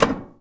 metal_tin_impacts_soft_04.wav